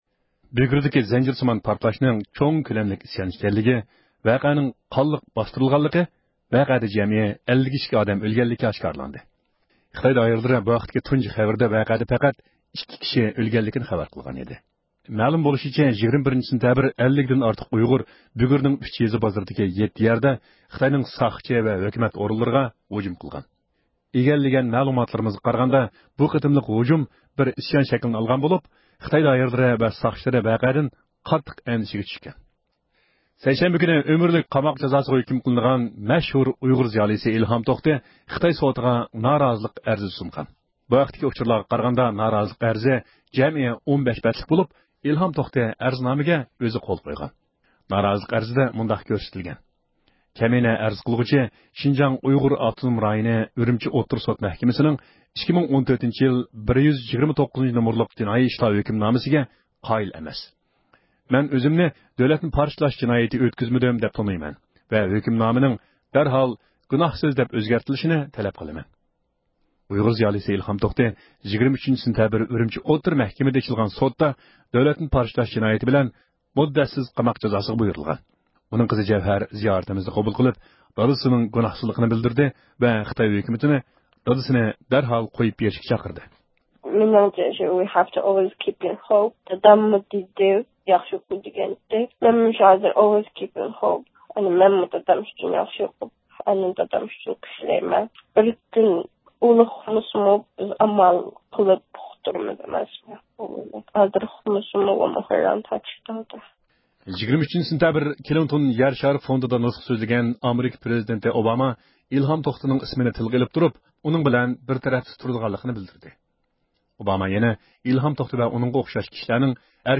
ھەپتىلىك خەۋەرلەر (20-سېنتەبىردىن 26-سېنتەبىرگىچە) – ئۇيغۇر مىللى ھەركىتى